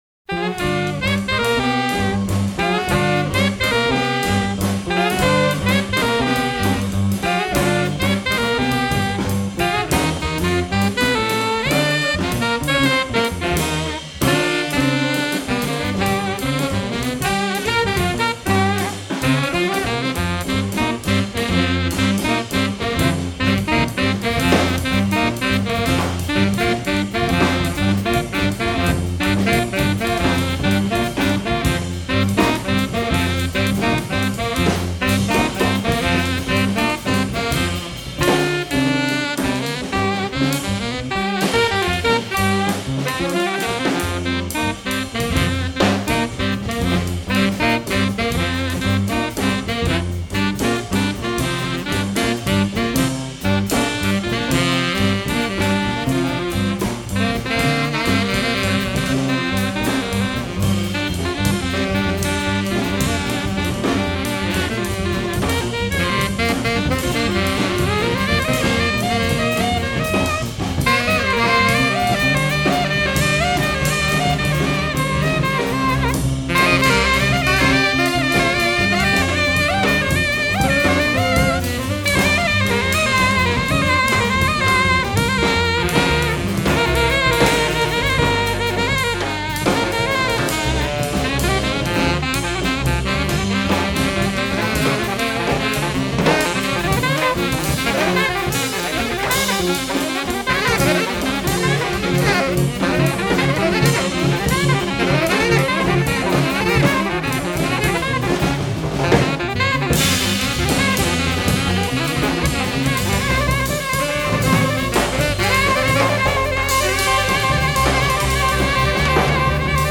It was live at this place Inroads.